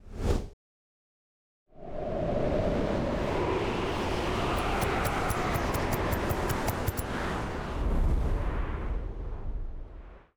SFX_Schlappentornado_01.wav